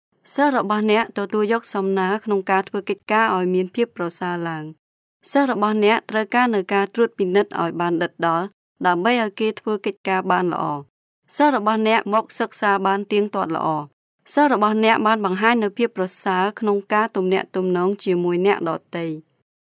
Sprecherin kambodschanisch.
Sprechprobe: eLearning (Muttersprache):
Professional female voice over artist from Cambodia.